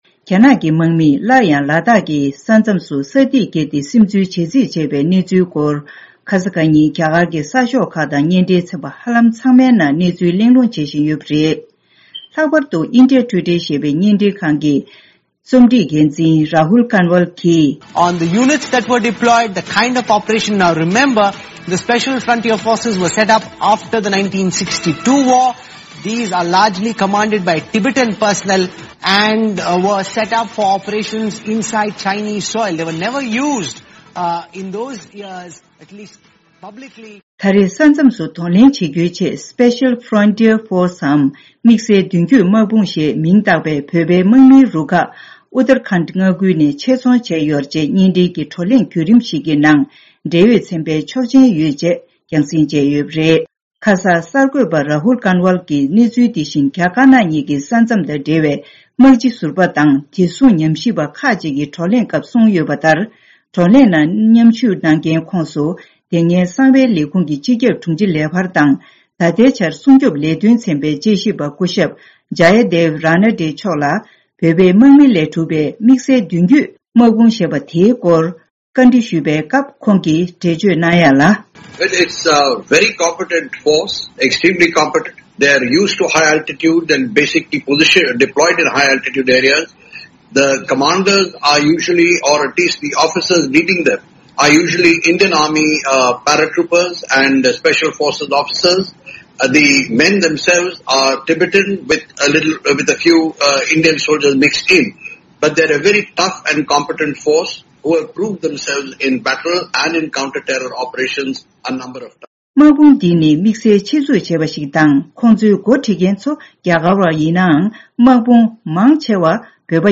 བཀའ་འདྲི་ཞུས་ཏེ་ཕྱོགས་བསྒྲིགས་ཞུས་པ་ཞིག